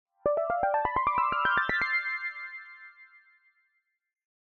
알림음 8_Ascending6.mp3